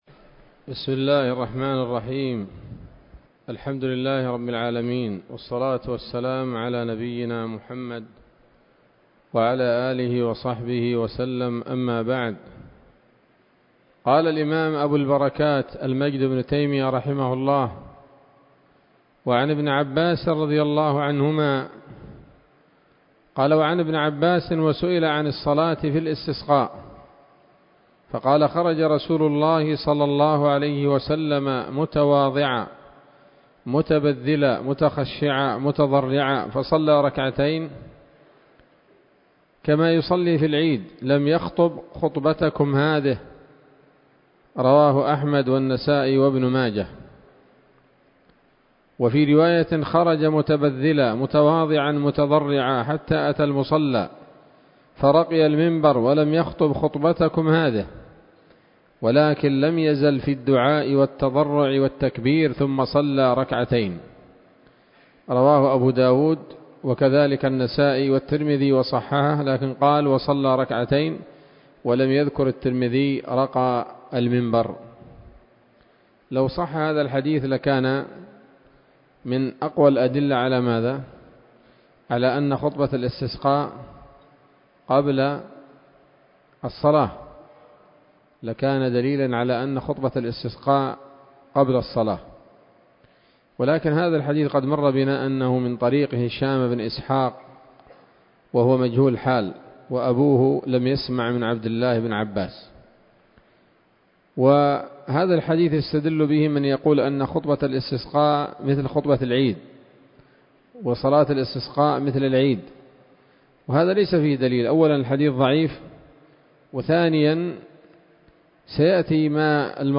الدرس الثالث من ‌‌‌‌كتاب الاستسقاء من نيل الأوطار